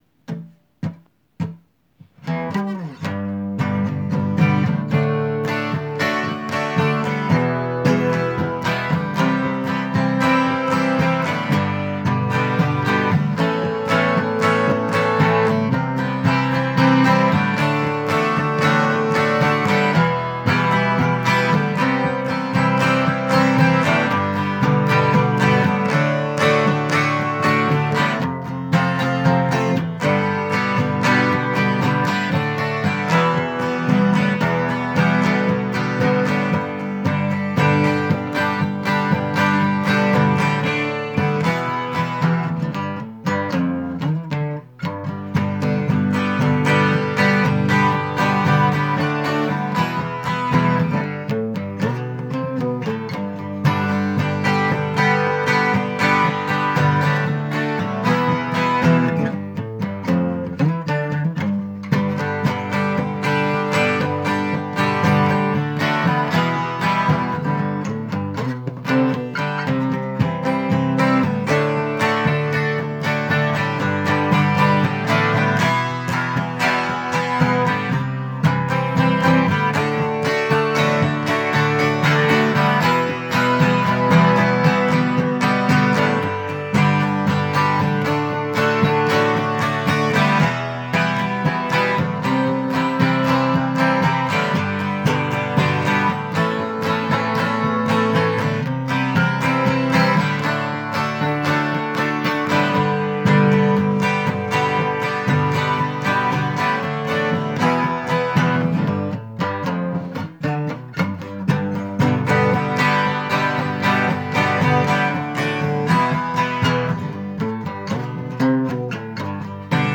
music_smorgasbord_nofreeride_acoustic.m4a